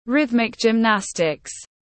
Môn thể dục nhịp điệu tiếng anh gọi là rhythmic gymnastics, phiên âm tiếng anh đọc là /ˌrɪð.mɪk dʒɪmˈnæs.tɪks/
Rhythmic gymnastics /ˌrɪð.mɪk dʒɪmˈnæs.tɪks/
Rhythmic-gymnastics.mp3